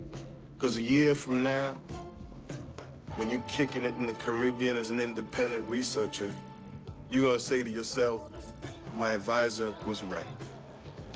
Guess which part is synthesized!